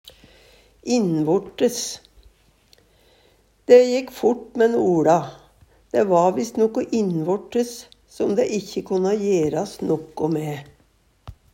innvortes - Numedalsmål (en-US)
DIALEKTORD PÅ NORMERT NORSK innvortes innvendig i menneskekroppen Eksempel på bruk Dæ jekk fort mæ'n OLa, dæ va visst noko innvortes som dæ ikkje konna jeras noko mæ.